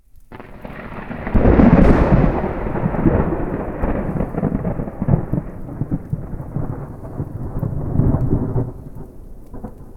thunder-4.ogg